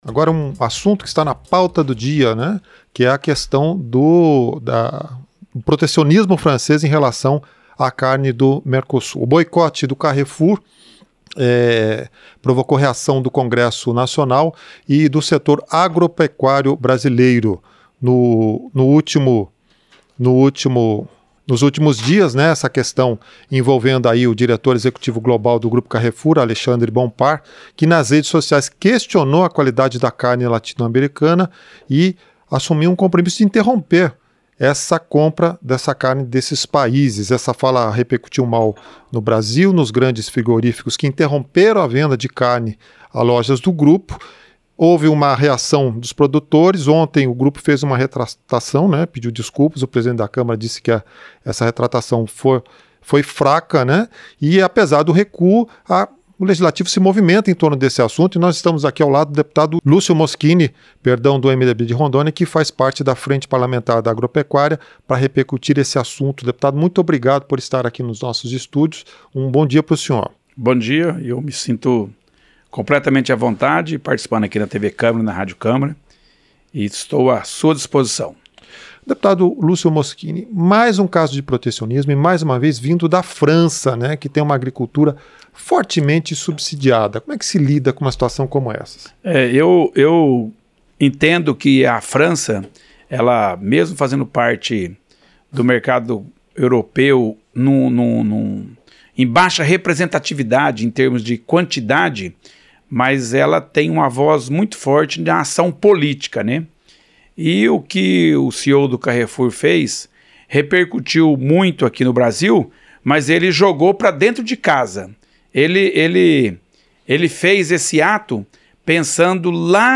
Entrevista - Dep. Lucio Mosquini (MDB-RO)